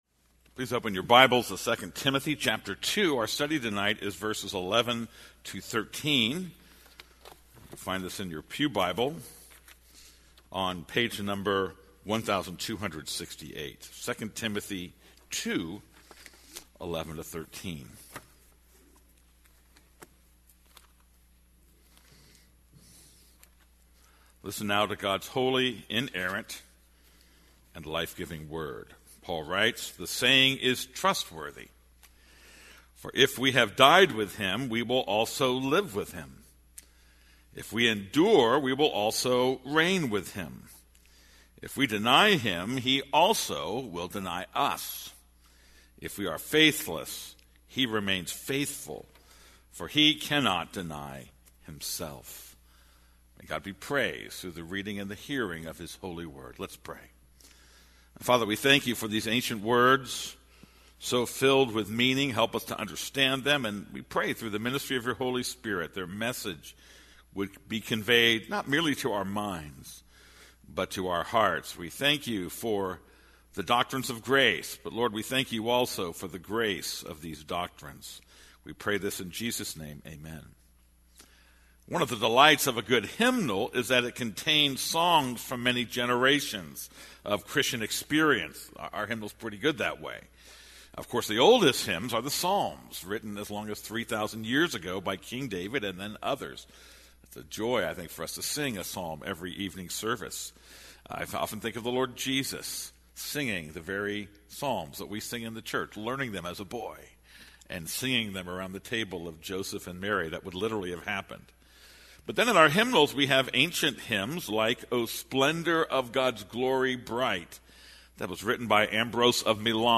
This is a sermon on 2 Timothy 2:11-13.